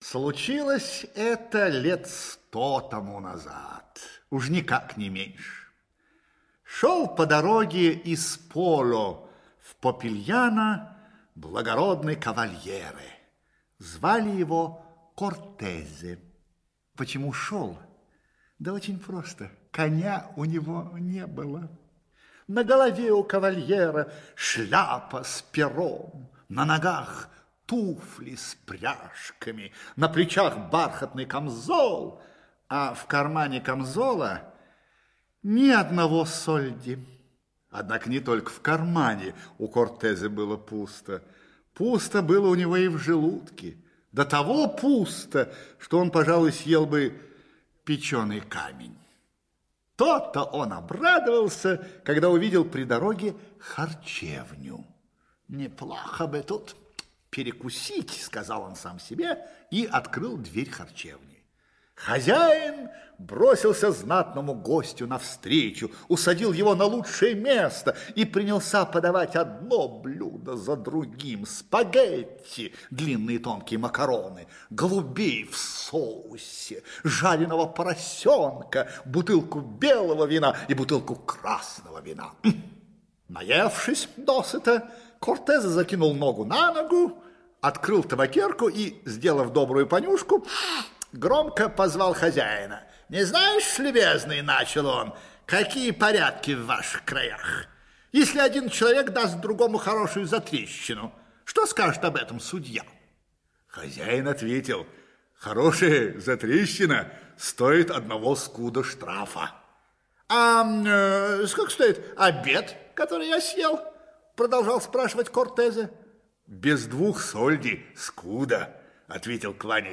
Как Кортезе заплатил за обед - итальянская аудиосказка - слушать онлайн